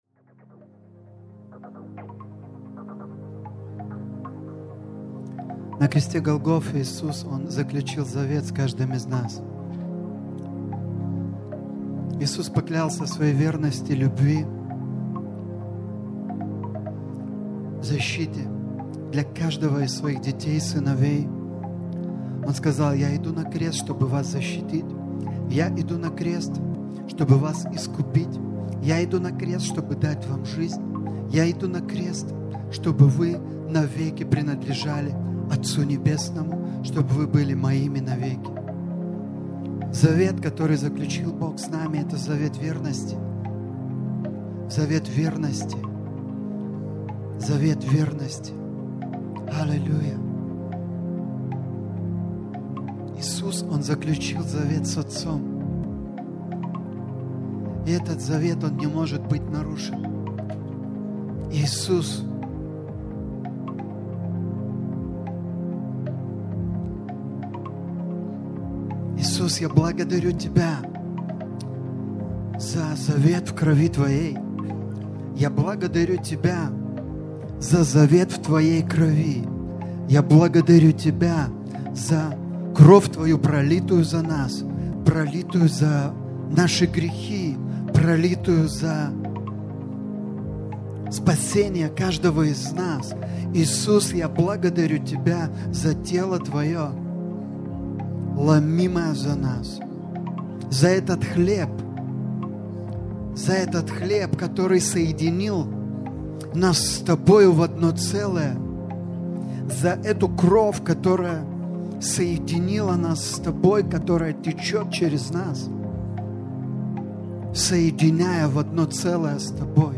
Worship & Prayer Maldos tarnavimas